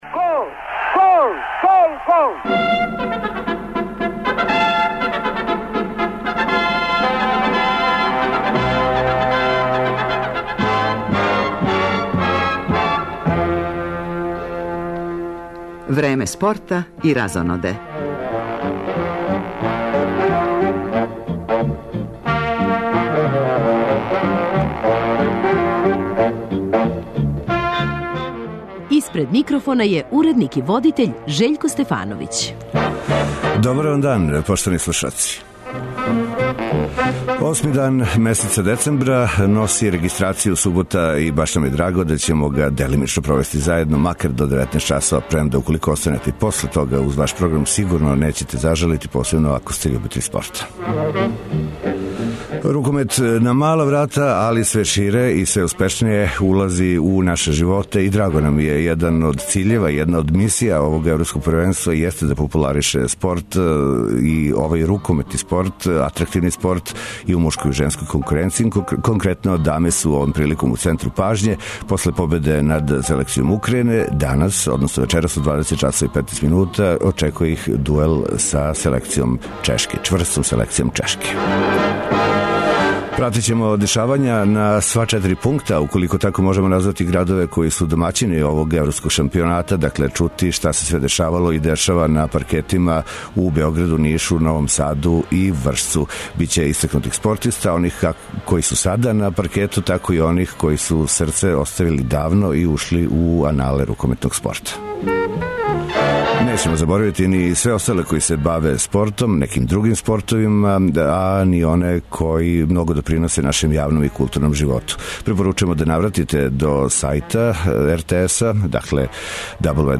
Поред изјава и актера поменуте утакмице, укључиваћемо и наше репортере са утакмица Норвешка - Украјина и Шведска - Македонија. Током емисије, представљамо две узорне спортске дружине - мушке рукометне клубове Рудар из Костолца и Југовић из Каћа. Пратићемо и отварање шампионата старог континента у шотокан каратеу, домаћин овог турнира је такође наша земља.